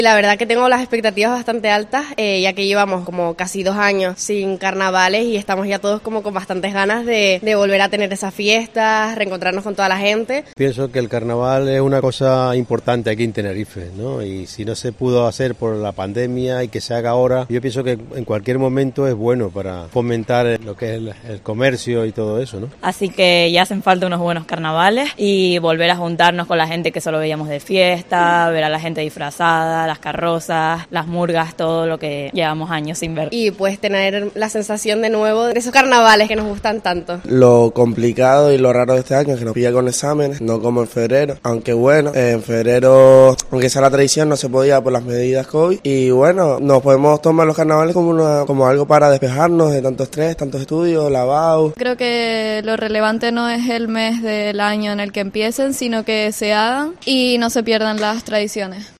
“Tengo las expectativas bastante altas porque llevamos casi dos años sin carnavales y estamos todos con bastantes ganas de retomar estas fiestas para reencontrarnos con toda la gente ”, dice una joven a COPE Tenerife consultada sobre su parecer de la singularidad de este año y el festejo capitalino, que parece compartir en entusiasmo con más chicharreros.
Pienso que cualquier momento es bueno para fomentar el comercio”, asegura un hombre agradecido por el empuje económico que el evento significa para la capital tinerfeña.